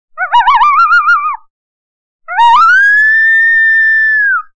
Звуки койота в естественной среде обитания